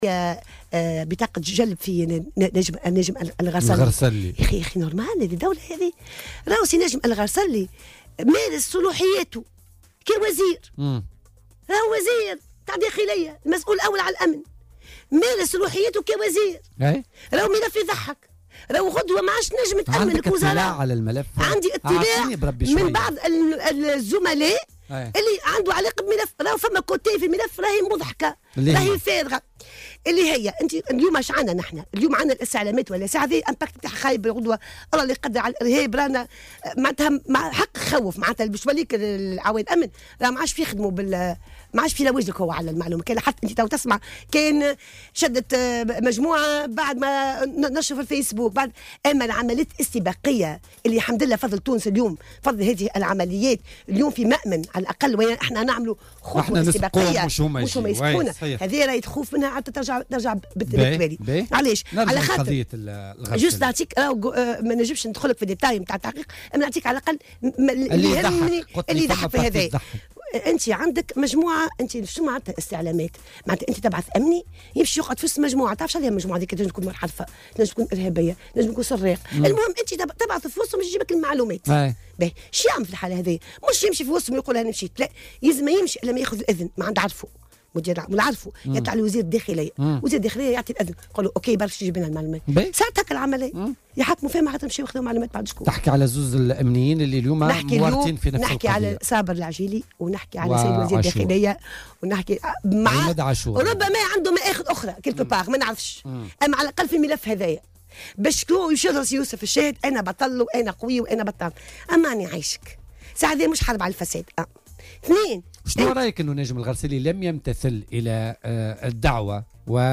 وانتقدت ضيفة "بوليتيكا" على "الجوهرة أف أم" صدور بطاقة جلب في حق ناجم الغرسلي وقالت إنه مارس صلاحياته بوصفه وزير الداخلية.